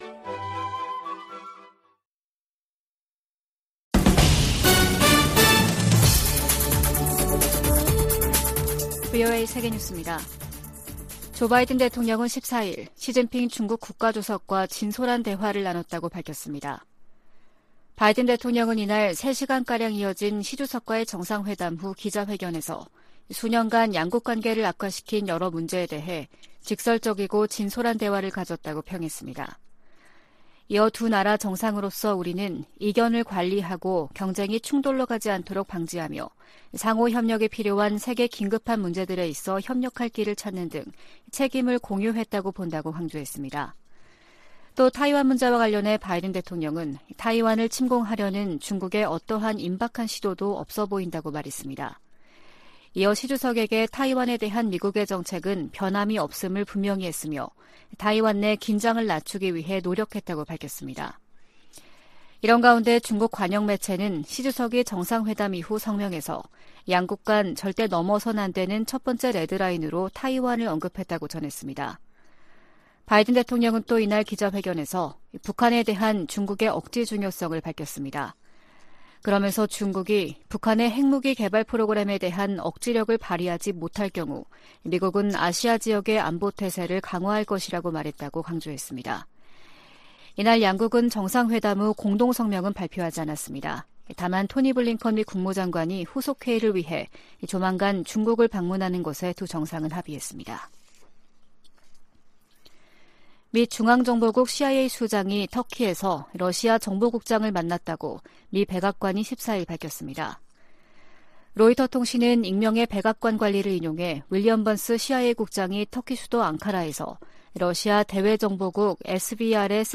VOA 한국어 아침 뉴스 프로그램 '워싱턴 뉴스 광장' 2022년 11월 15일 방송입니다. 미국과 한국, 일본 정상들이 미한일 정상이 13일 캄보디아에서 만나 북한 문제와 관련해 억제력을 강화하기 위해 협력하기로 합의했습니다. 미,한,일 정상의 ‘프놈펜 공동성명’은 북한의 고조된 핵 위협에 대응한 세 나라의 강력한 공조 의지를 확인했다는 평가가 나오고 있습니다.